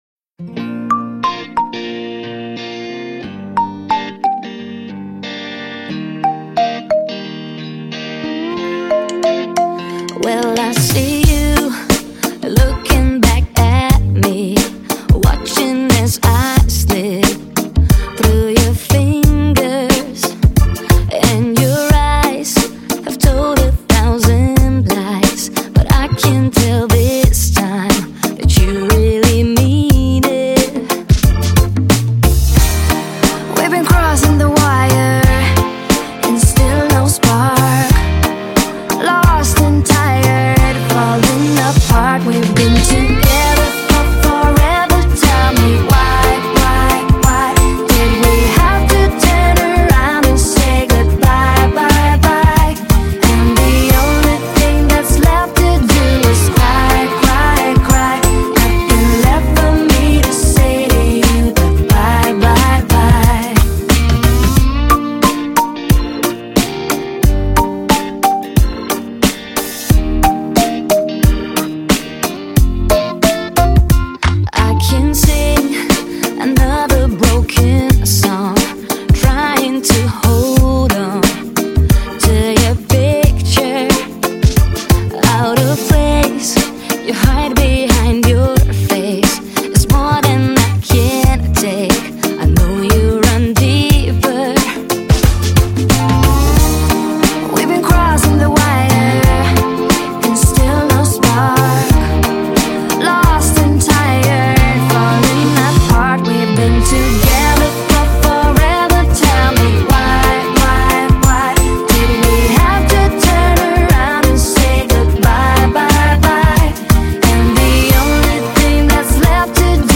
整张专辑洋溢着轻快的流行节奏~让人放松愉快~